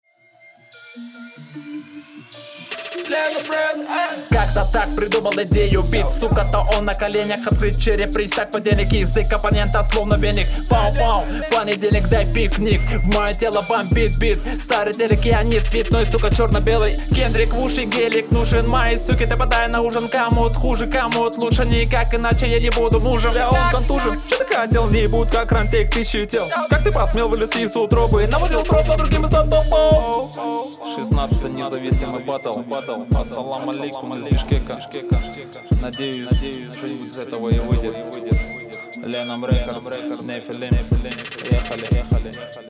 Серый трек на общем уровне, мысли банальны, читка средняя